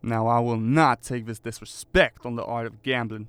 Voice Lines
Update Voice Overs for Amplification & Normalisation